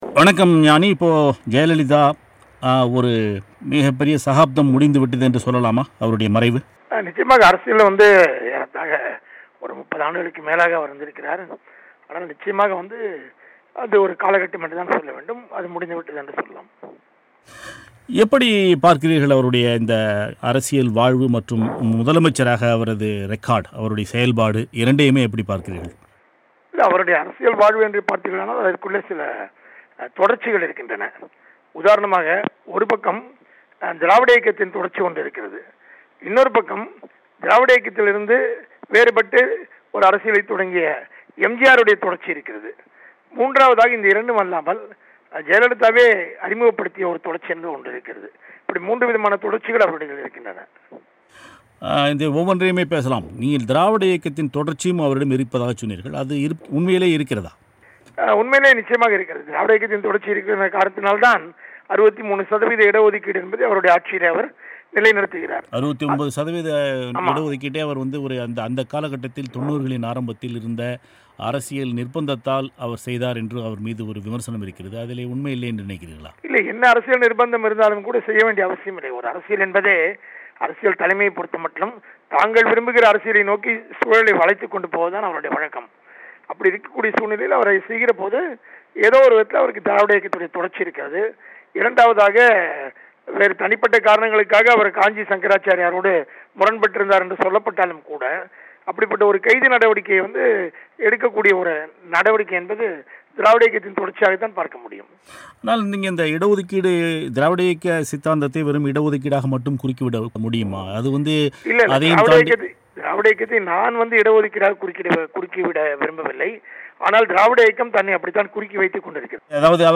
ஜெயலலிதாவின் அரசியல் வாழ்க்கை குறித்து ஞாநியின் பேட்டி